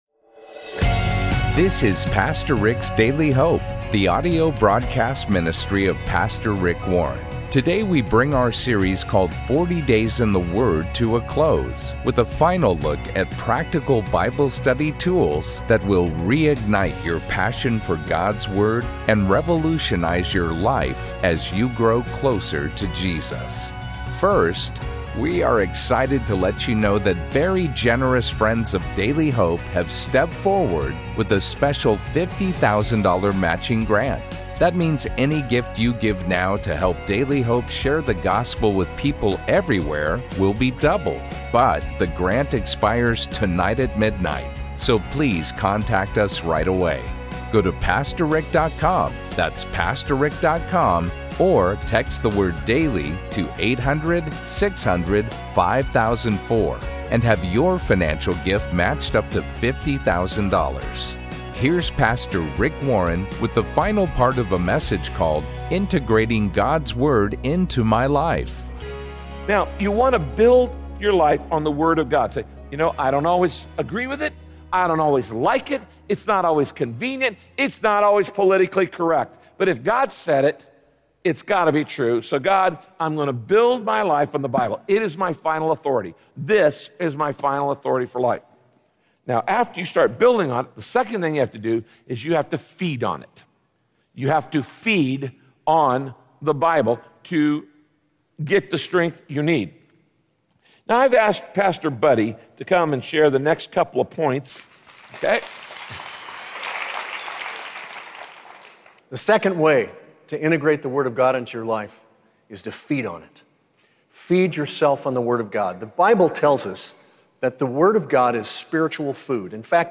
My Sentiment & Notes Integrating God’s Word Into My Life - Part 2 Podcast: Pastor Rick's Daily Hope Published On: Fri Jun 30 2023 Description: Satan doesn’t mind you studying the Bible as long as you don’t do anything with what you’ve learned. In this teaching from Pastor Rick, find out what you need to do to make sure that doesn’t happen.